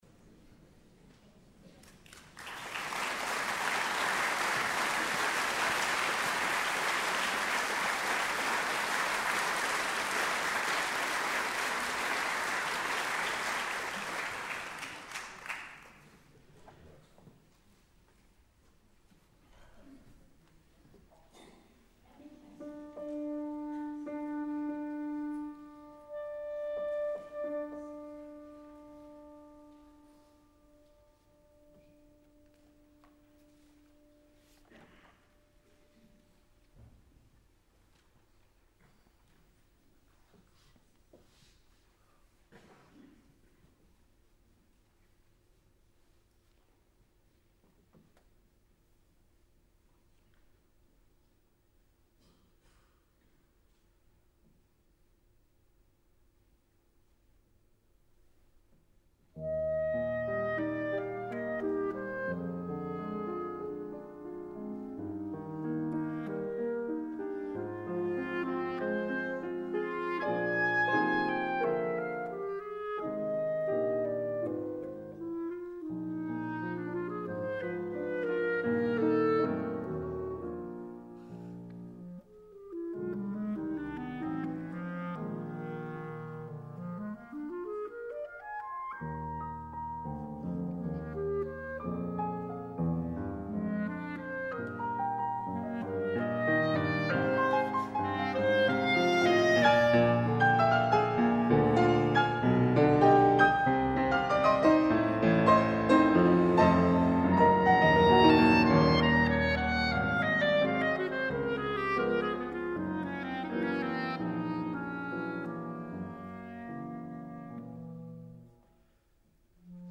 Clarinette
Brahms | Sonata for clarinet and piano op.120 n°1 in F minor
Live, Auditorium du Louvre 2012
Brahms-Sonate-n2-Auditorium-du-Louvre1.mp3